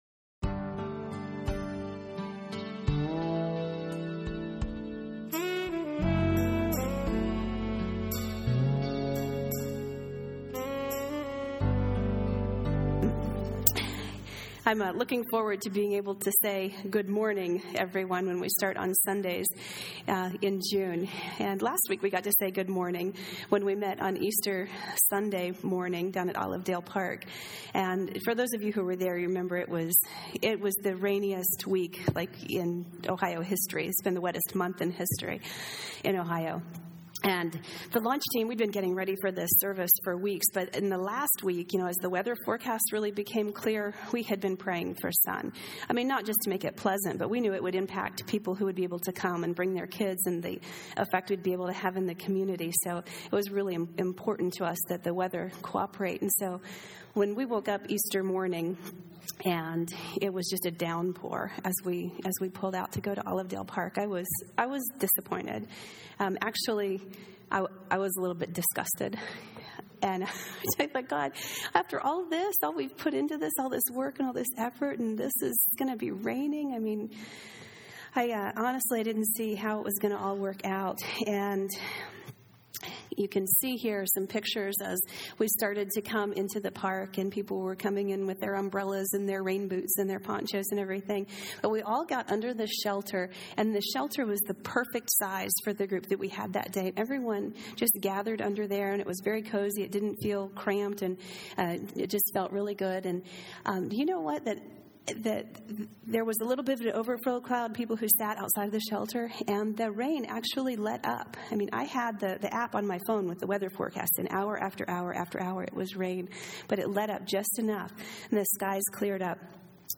Road-to-Emmaus-message.mp3